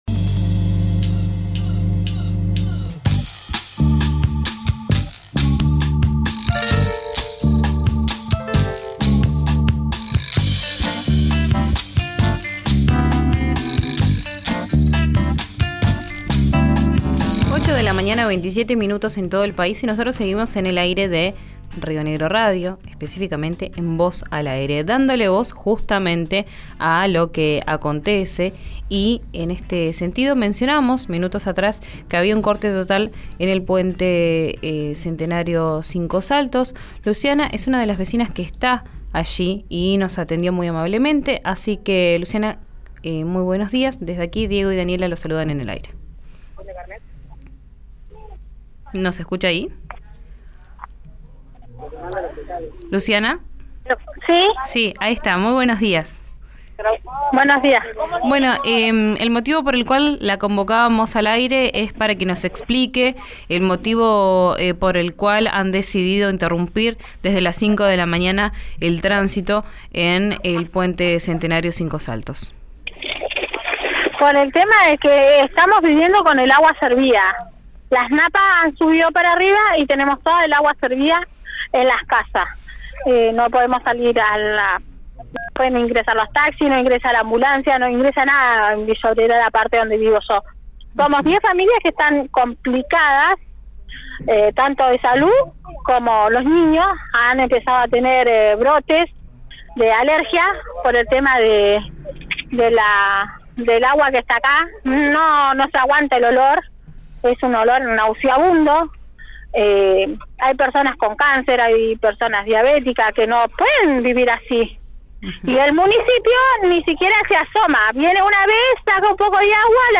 Levantaron el corte en el puente Centenario – Cinco Saltos